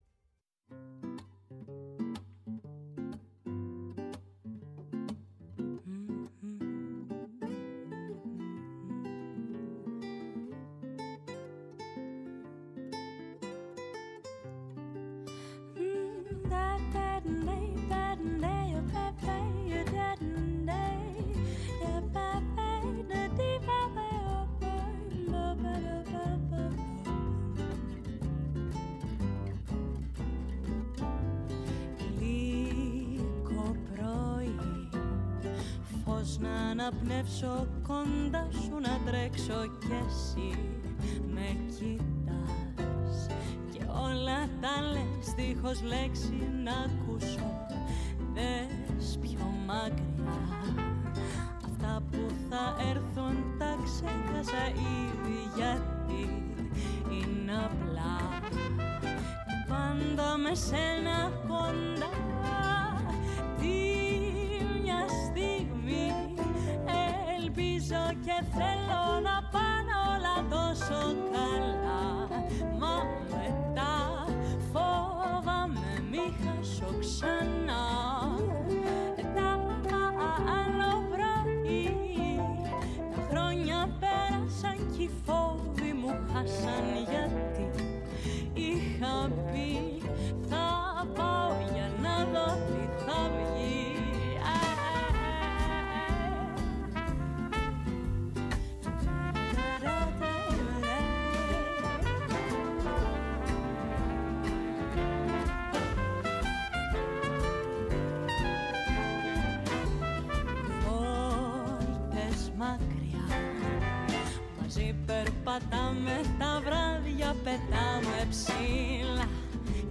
Στην εκπομπή φιλοξενήθηκε τηλεφωνικά από τα Χανιά,